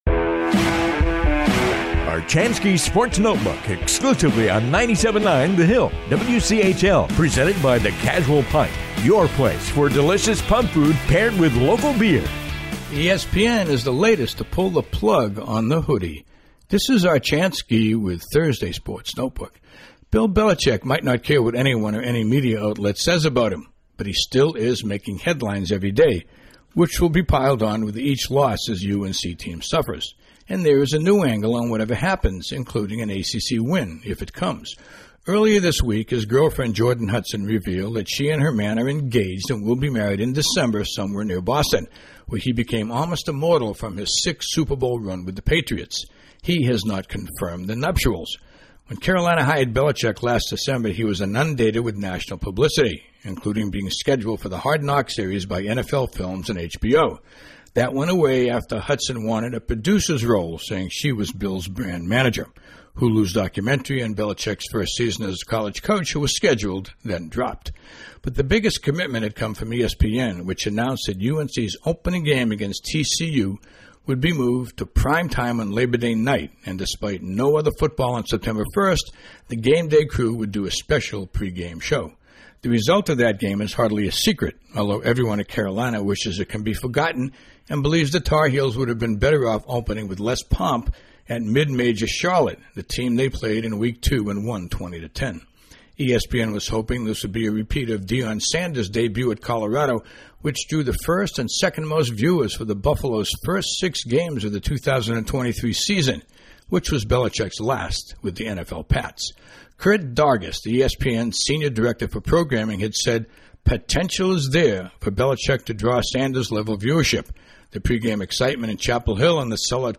commentary airs daily on the 97.9 The Hill WCHL